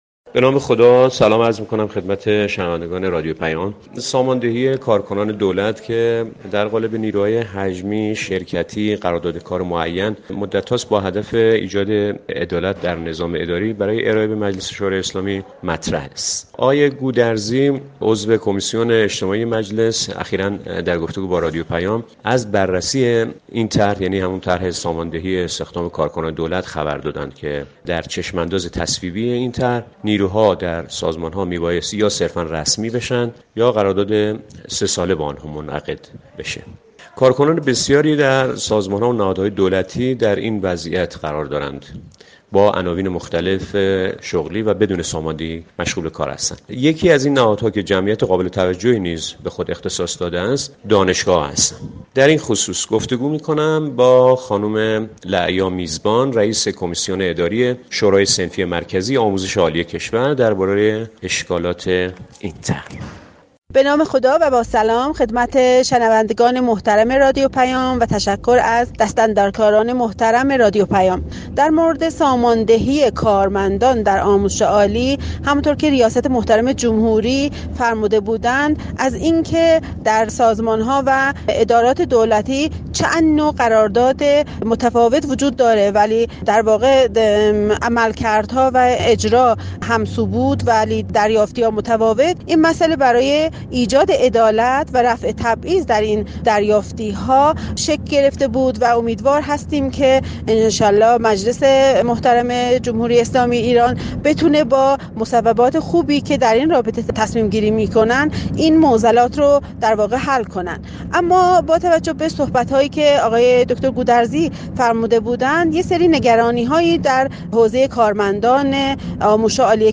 گفتگوی اختصاصی با رادیو پیام